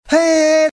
A vocoder was used on “pacifico” and then on “politica”, you can hear pre and post-treatment here. A full organ+string sound was used as the carrier to give the herbal voice.
politica-vocod.mp3